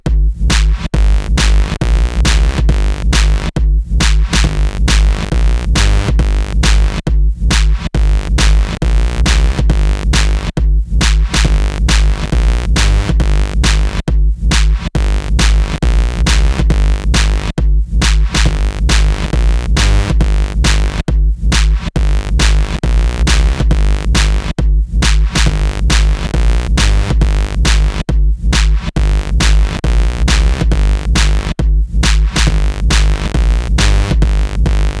Жанр: Танцевальные / Электроника / Техно
Dance, Electronic, Techno